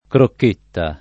vai all'elenco alfabetico delle voci ingrandisci il carattere 100% rimpicciolisci il carattere stampa invia tramite posta elettronica codividi su Facebook crocchetta [ krokk % tta ] s. f. — adattam. del fr. croquette